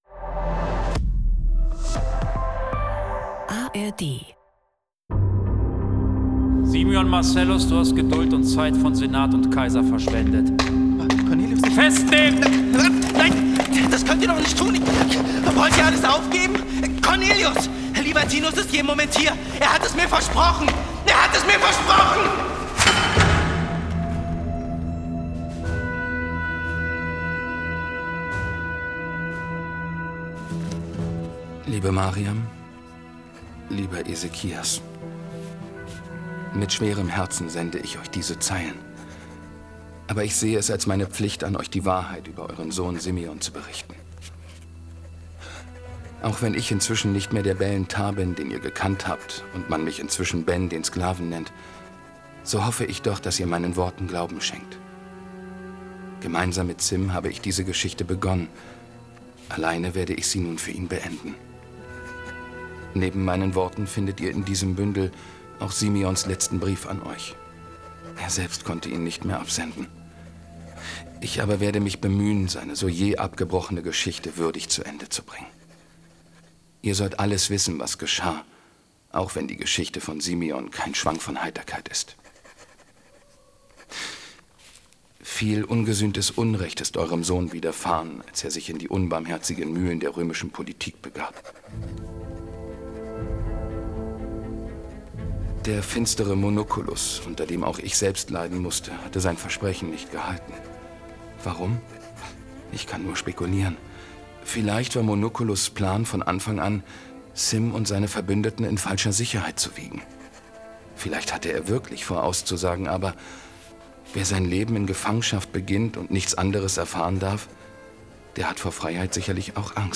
Ein packender Hörspiel-Podcast im Anno-Universum: Zwei junge Männer verkaufen sich selbst in die Sklaverei – im Glauben, dass sie im Römischen Reich aufsteigen können.